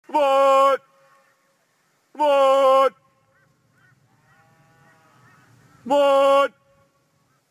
appel vache.mp3
appel-vache.mp3